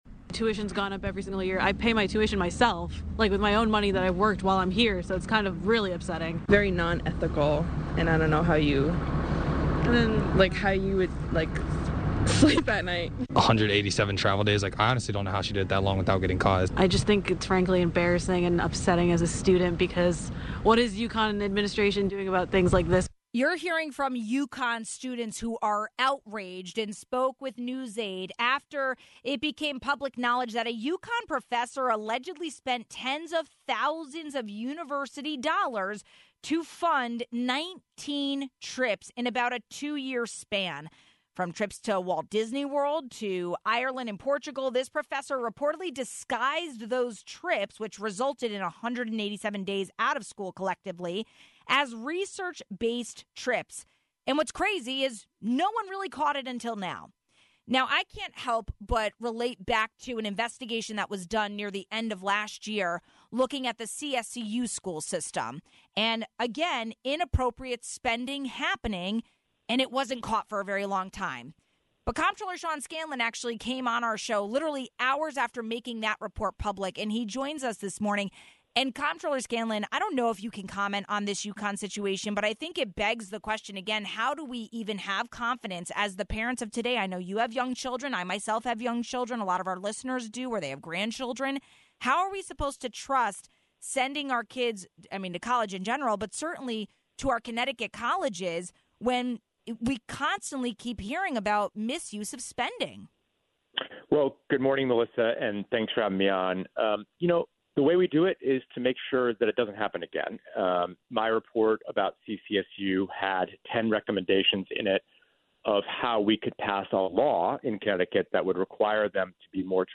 It reminds me of the findings of a CSCU investigation on inappropriate spending conducted by Comptroller Sean Scanlon. We spoke with him about ways to avoid inappropriate spending at the university level moving forward. We also touched on a potential child tax credit in CT as well as more proposed benefits for rideshare drivers.